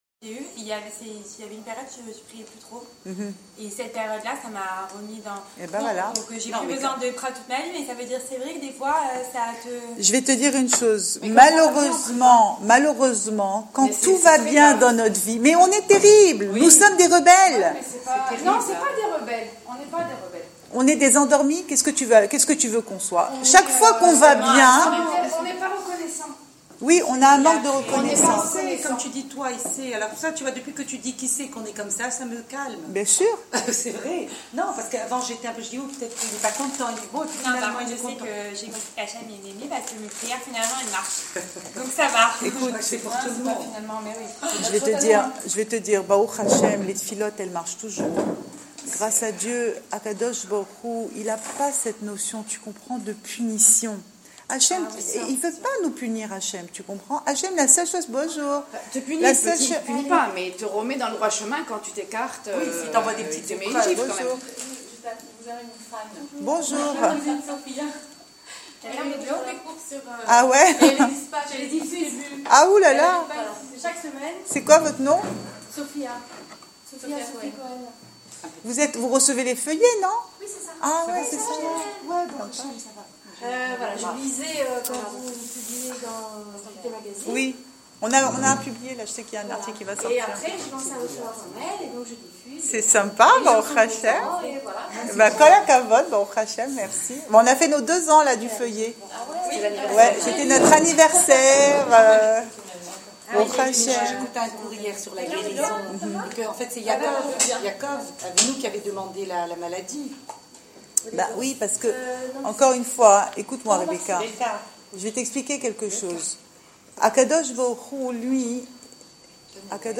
Cours audio Emouna Le coin des femmes - 17 mars 2015 15 juillet 2015 Suis-je encore une esclave ? Enregistré à Raanana Share on Facebook Share Share on Twitter Tweet Post navigation Previous article Numéro 50 – Freedom ?